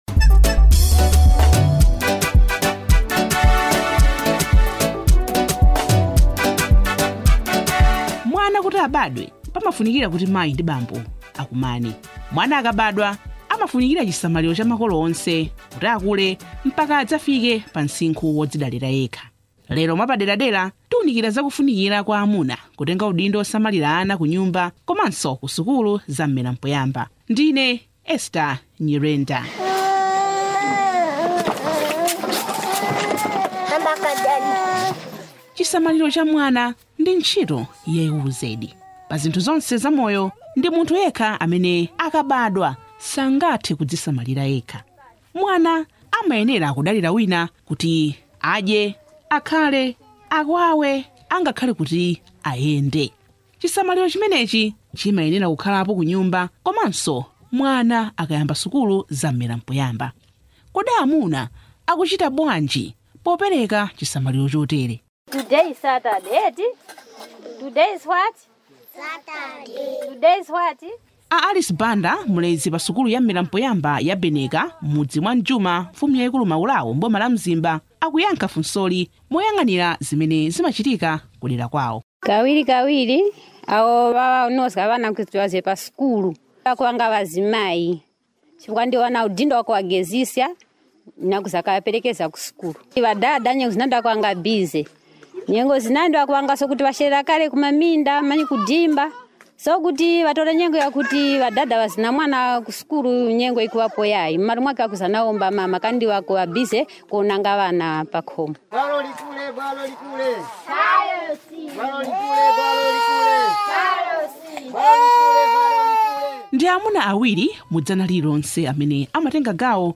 ECD DOCUMENTARY ON MALE INVOLVEMENT
ECD DOCUMENTARY ON MALE INVOLVEMENT.mp3